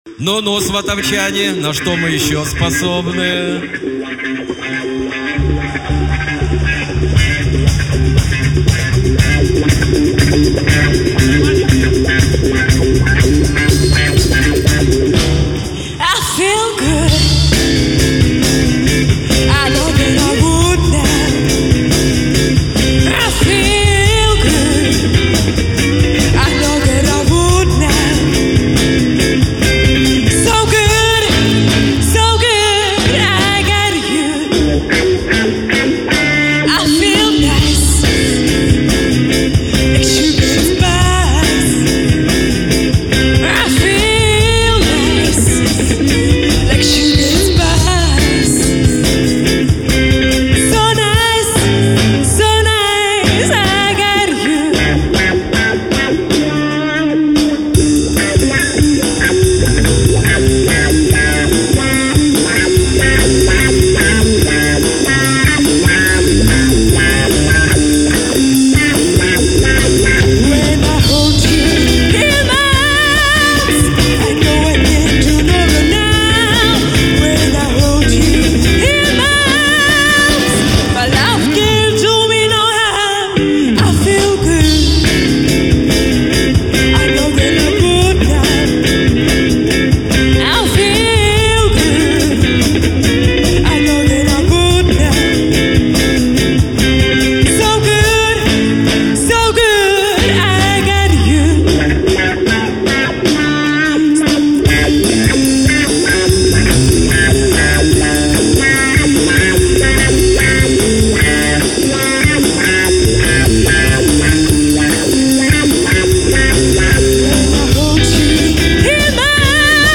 Потому не судите строго-это не студийные вылизанные записи-это как в жизни- по всякому.
снова сватовчане.